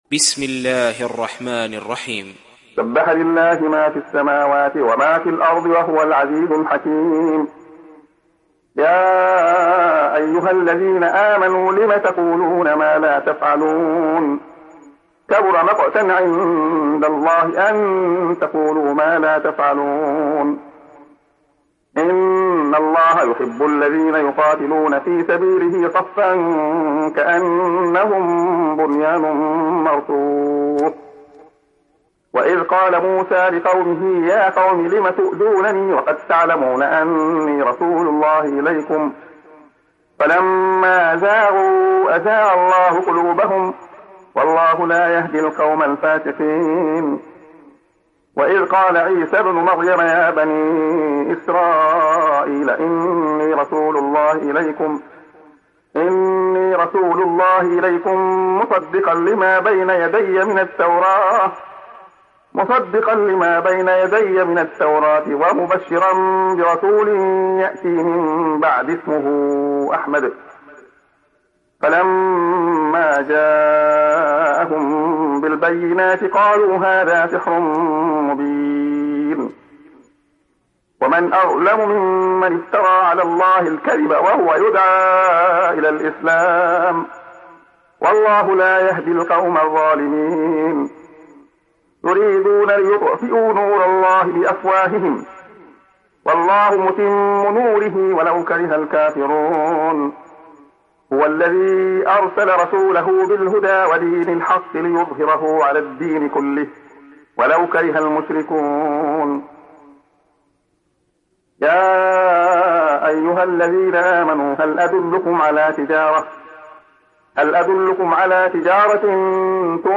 Saf Suresi İndir mp3 Abdullah Khayyat Riwayat Hafs an Asim, Kurani indirin ve mp3 tam doğrudan bağlantılar dinle